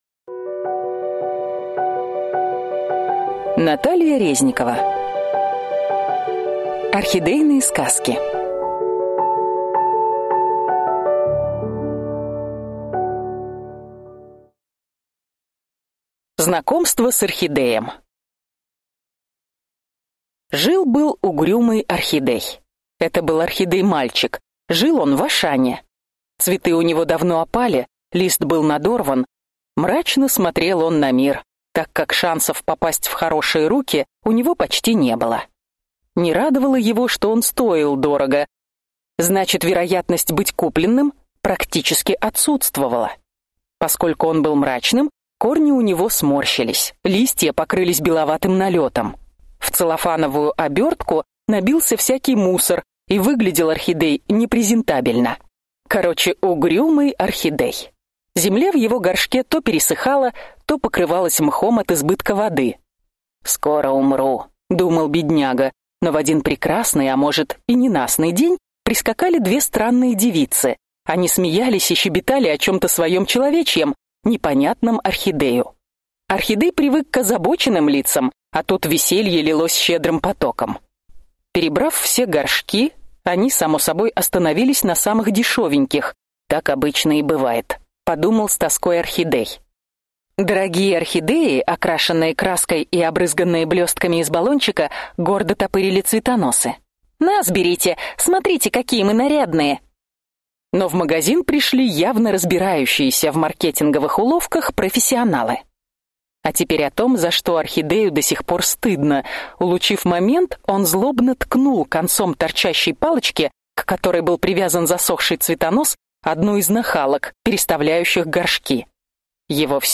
Аудиокнига Орхидейные сказки для взрослых и детей | Библиотека аудиокниг